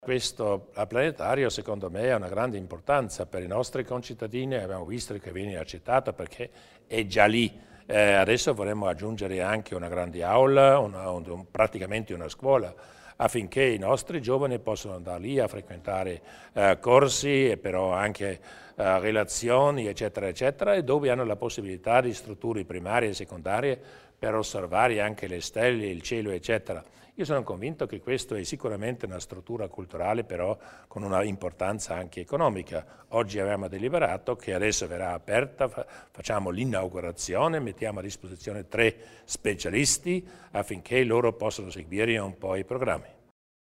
Il Presidente Durnwalder illustra il futuro del planetario di San Valentino